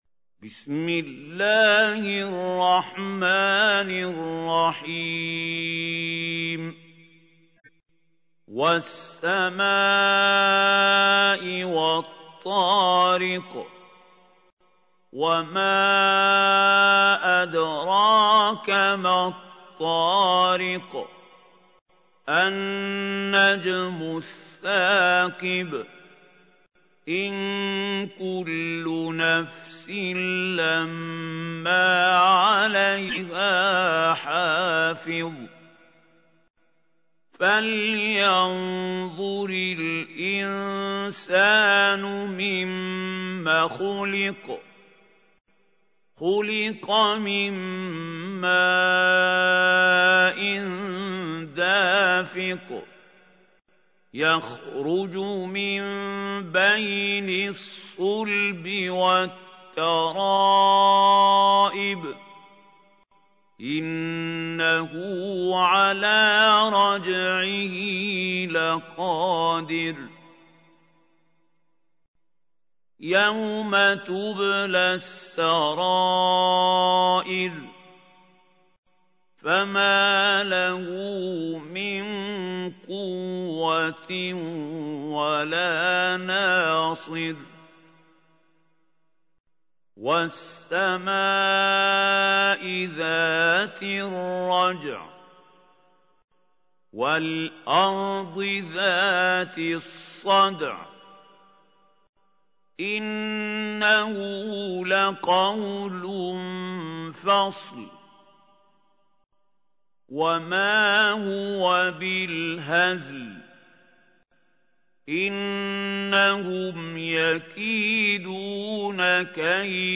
تحميل : 86. سورة الطارق / القارئ محمود خليل الحصري / القرآن الكريم / موقع يا حسين